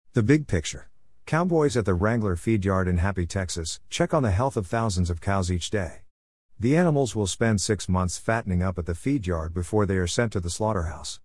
AmazonPollyAudio